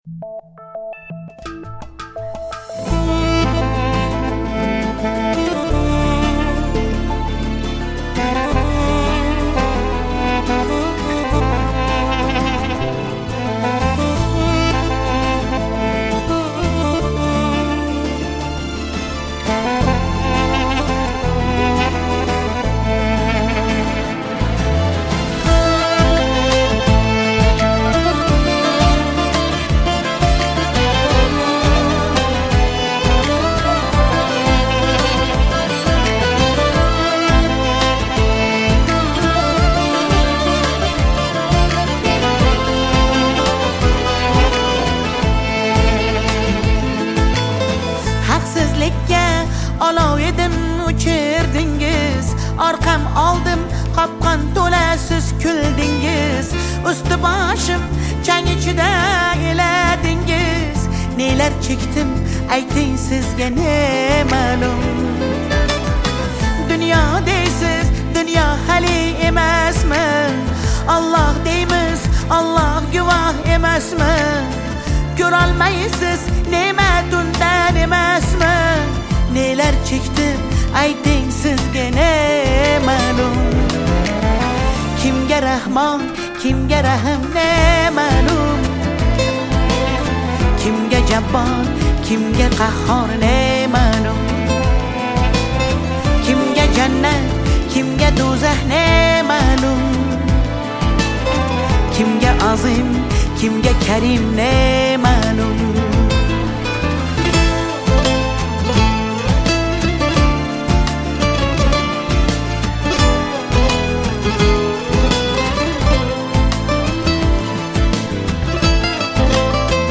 • Категория: Узбекская музыка